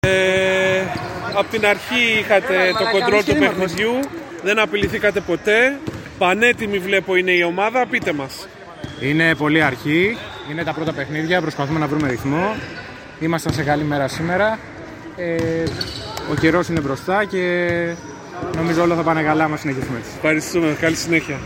GAMES INTERVIEWS
Παίκτης Aegean